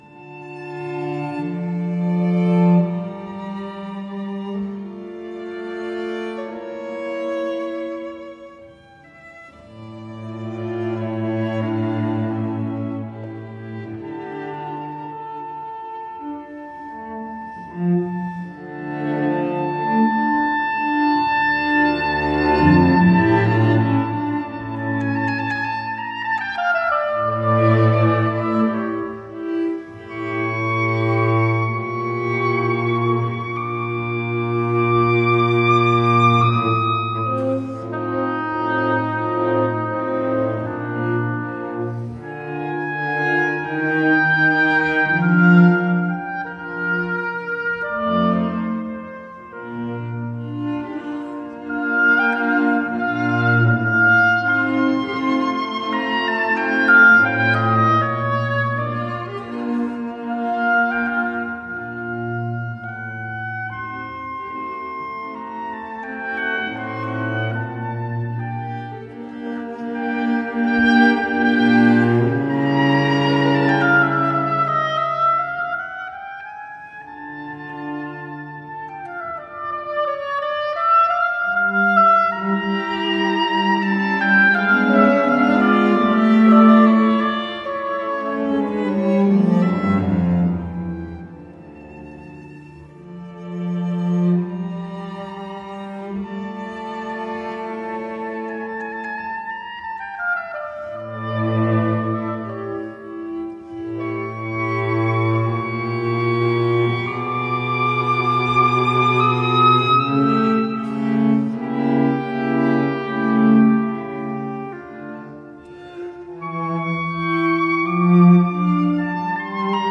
MOZART, quatuor pour hautbois et cordes k 370, 02 andante.mp3